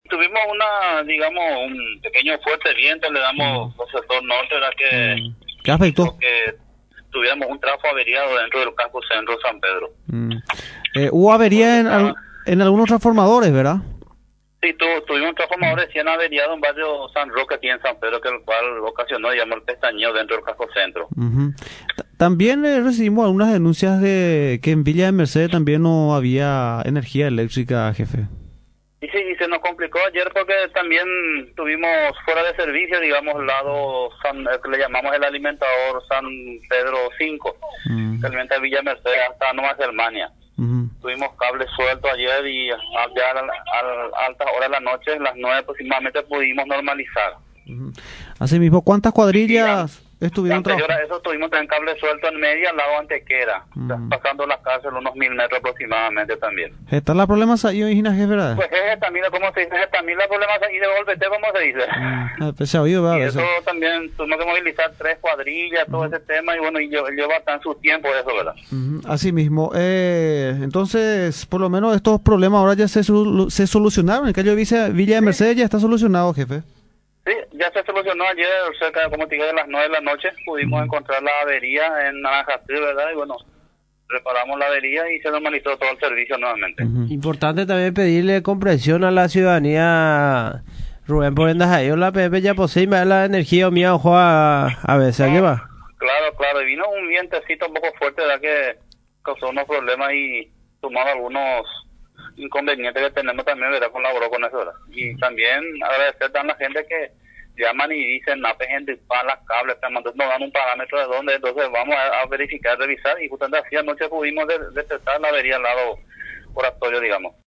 en conversación con Radio Nacional